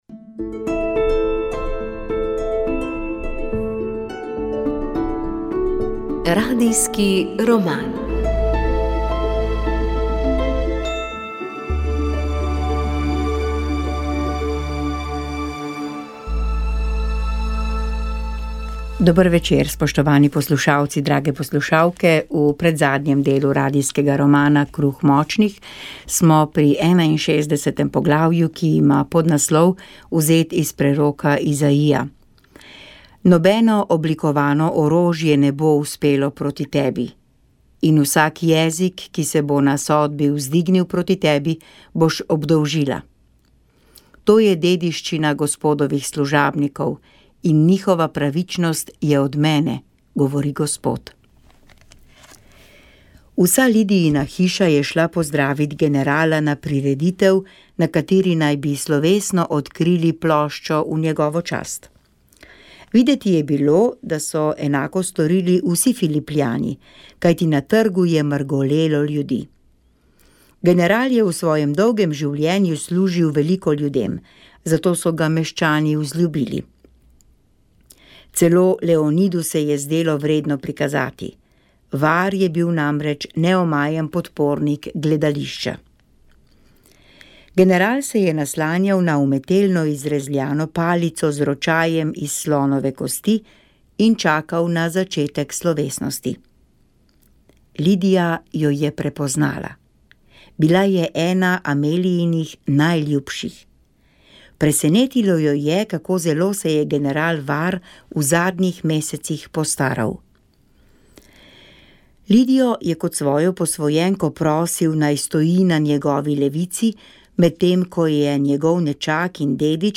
V sklopu kulturnega poletnega festivala v Poletnem gledališču Studenec gledališka skupina Kulturnega društva Miran Jarc iz Škocjana pri Domžalah vsako leto pripravi domačo gledališko predstavo. Letos je to komedija s petjem Čevljar baron. Na dan premiere smo se pogovarjali